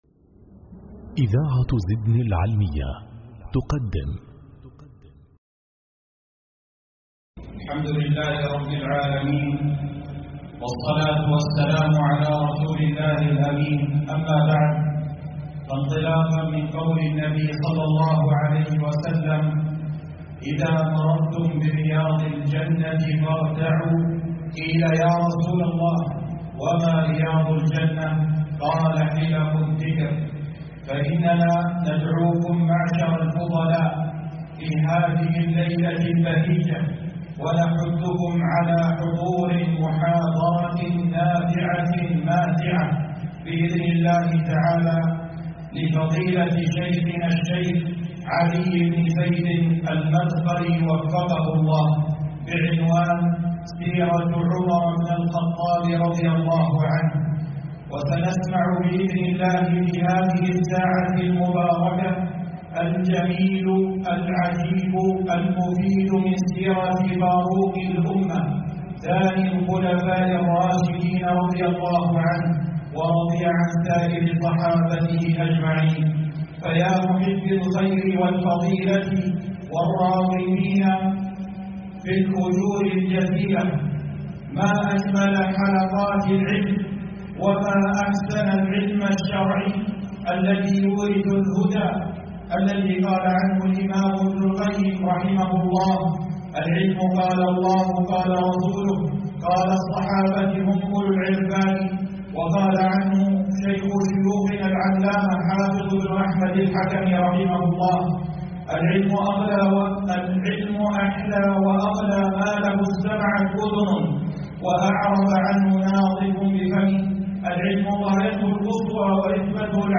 محاضرة
مسجد هيا الحمدان بقرية العسيلة بأبو عريش